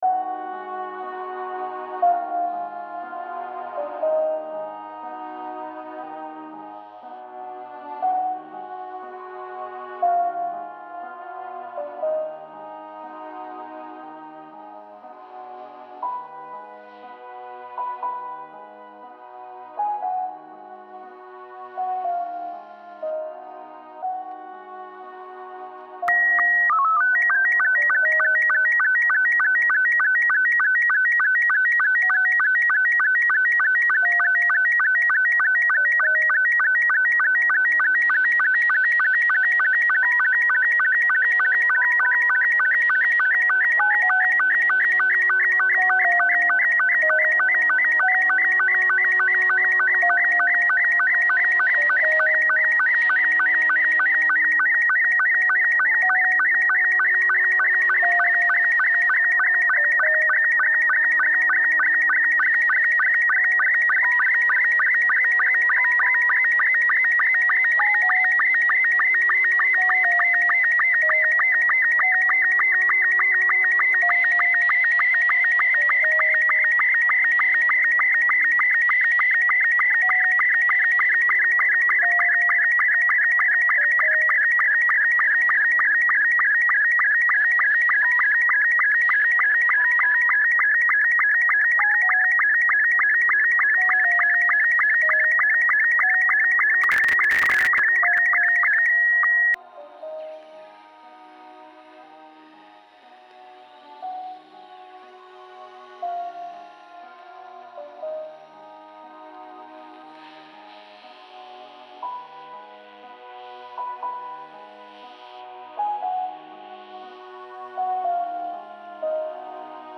ten jego plik, który zaczyna się od muzyczki, ale potem następują dźwięki rodem z taśmy ZX Spectrum.
Szybkie śledztwo wykazało, że te dźwięki to obraz zakodowany prastarą technologią amatorskich radiostacji HAM, znaną jako Slow Scan TV.